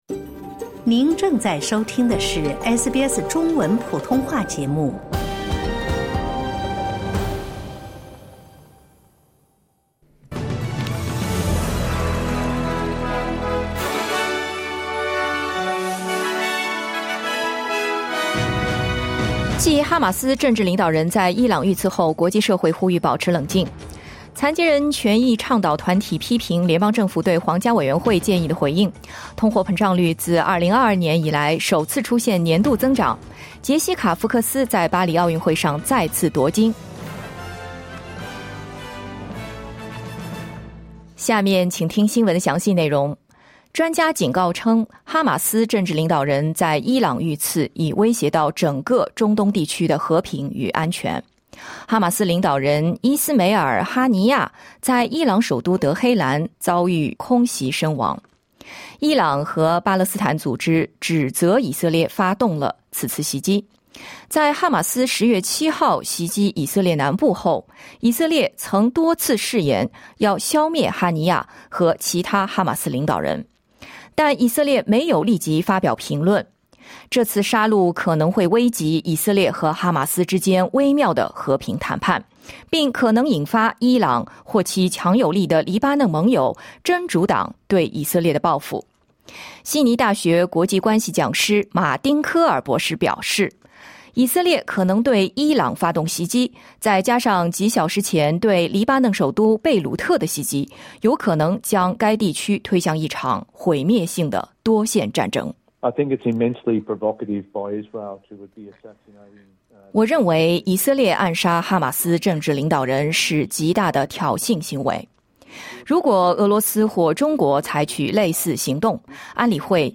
SBS早新闻 (2024年8月1日)